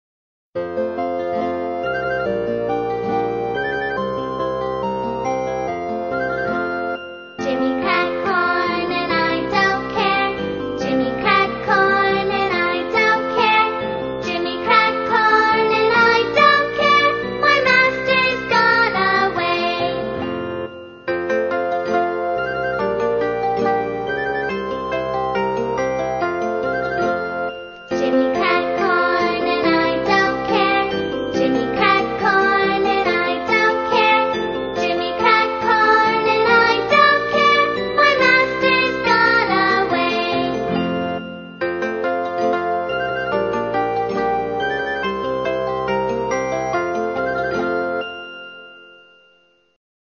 在线英语听力室英语儿歌274首 第106期:Jimmy crack corn(2)的听力文件下载,收录了274首发音地道纯正，音乐节奏活泼动人的英文儿歌，从小培养对英语的爱好，为以后萌娃学习更多的英语知识，打下坚实的基础。